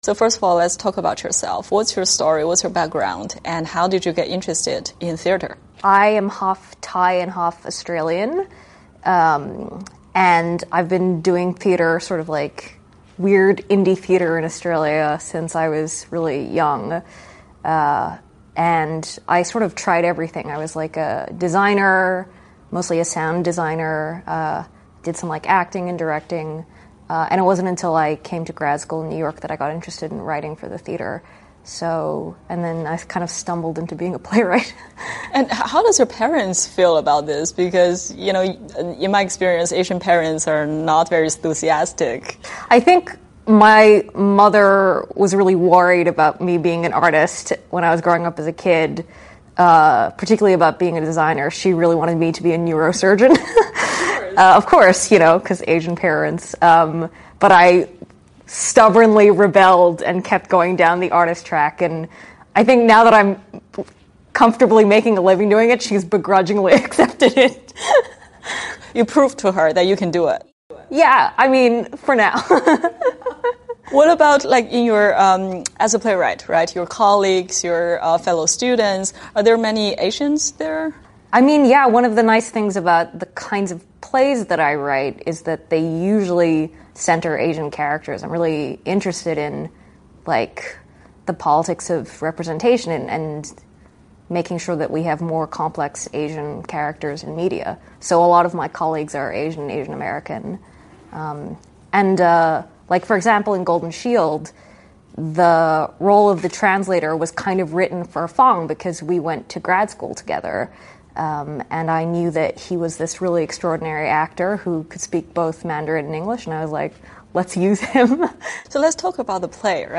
VOA专访